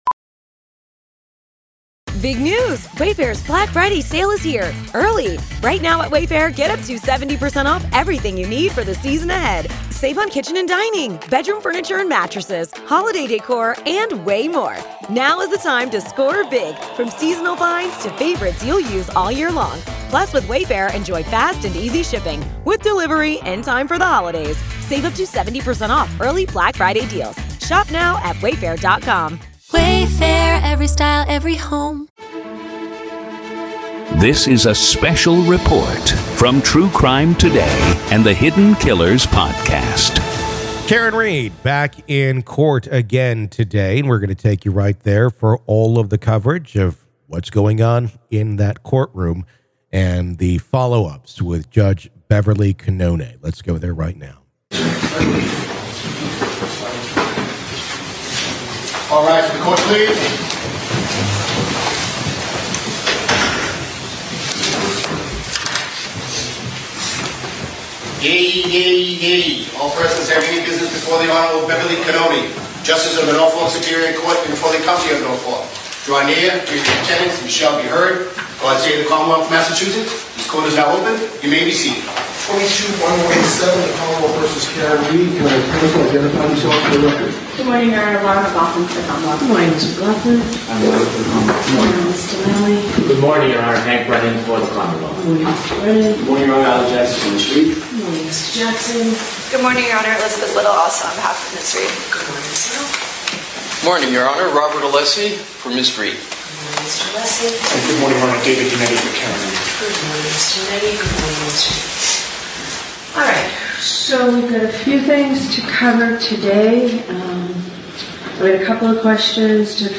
MA v. Karen Read Murder Retrial - Motions Hearing Day 1 PART 1